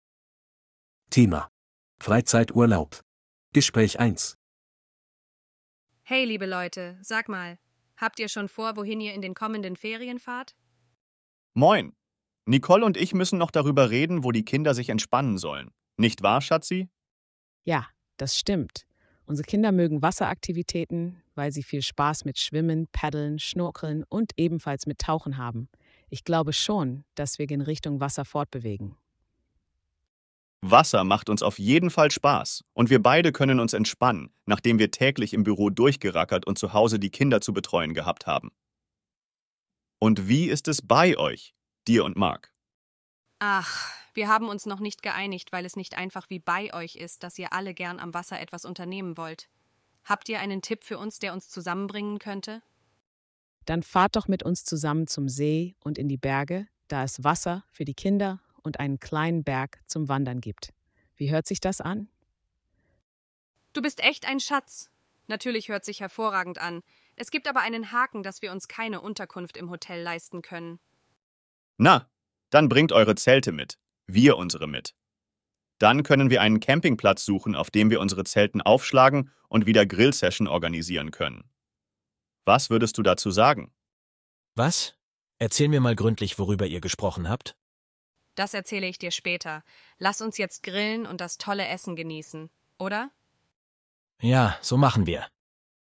Audio text conversation 1:
A2-Kostenlose-R-Uebungssatz-7-Freizeit-Urlaub-Gespraech-1.mp3